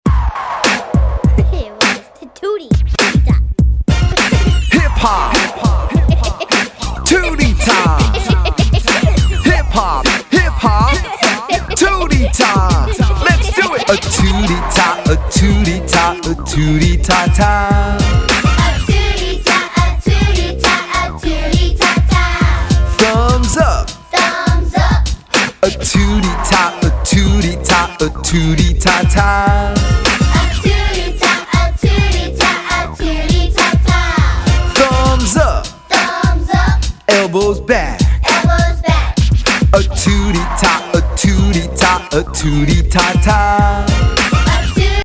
Hip-Hop and Pop Beats to Help Your Children Read